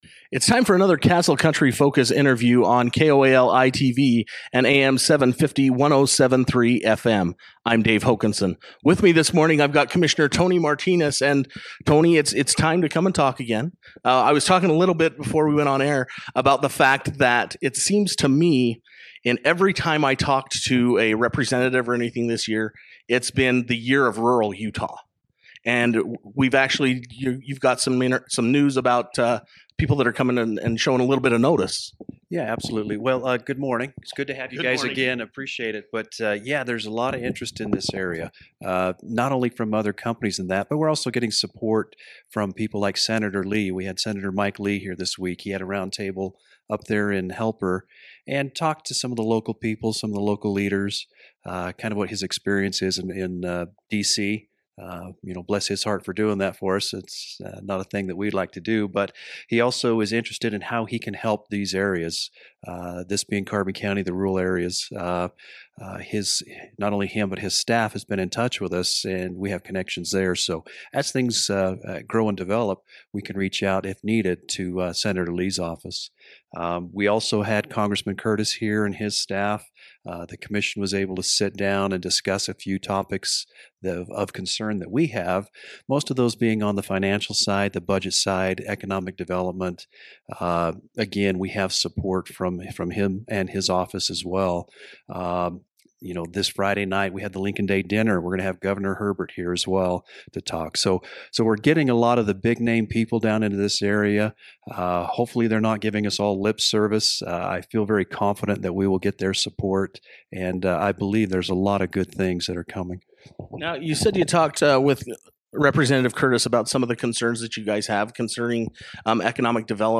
Carbon County Commissioner Tony Martines took time to speak with Castle Country Radio about the various political figures that have made some recent visits to the Castle Country area.